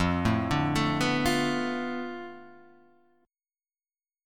F6b5 chord